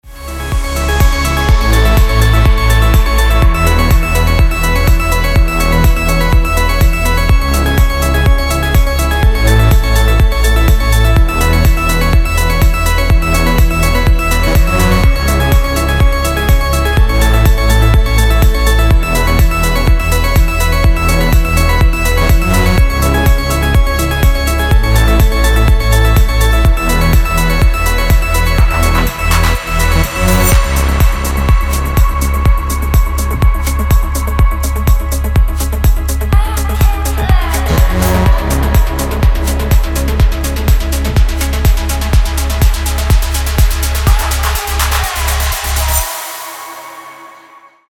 • Качество: 320, Stereo
громкие
Electronic
EDM
без слов
Стиль: progressive house